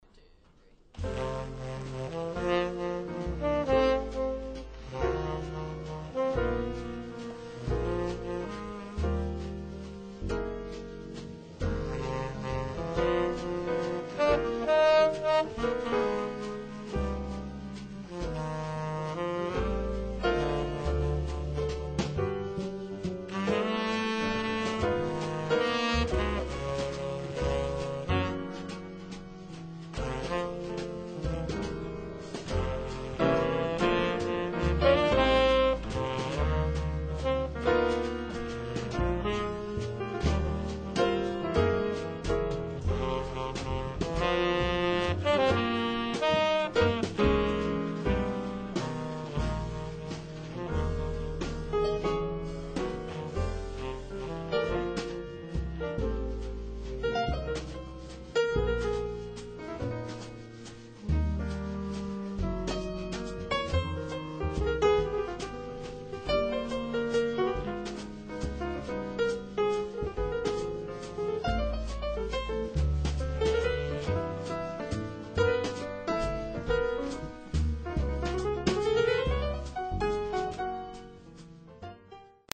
Los Angeles Jazz Pianist 7
This Los Angeles Jazz Pianist is known among her peers for swinging every note, subtle phrasing, and honest, lyrical writing.